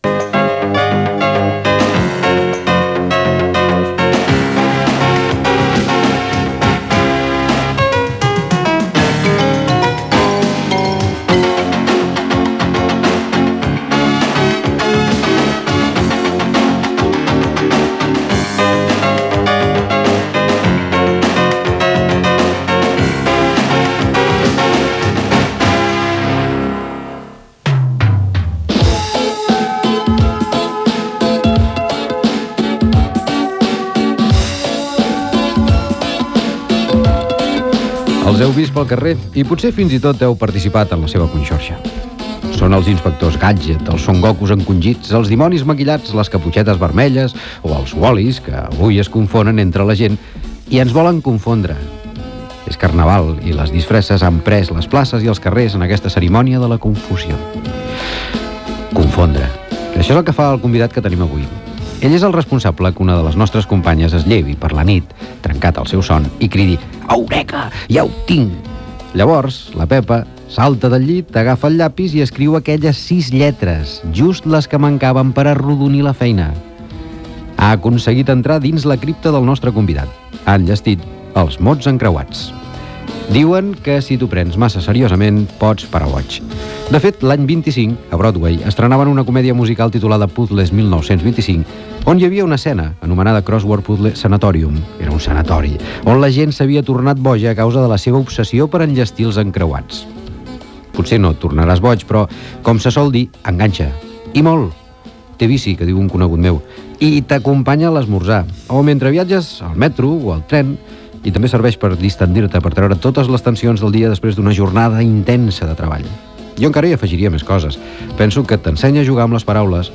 Indicatiu de l'emissora, comentari sobre el Carnaval, la confusió i els mots encreuats, indicatiu del programa, entrevista a l'escriptor Màrius Serra, indicatiu, publicitat i indicatiu